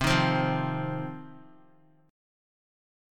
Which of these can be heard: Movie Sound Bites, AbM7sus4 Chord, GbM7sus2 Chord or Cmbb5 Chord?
Cmbb5 Chord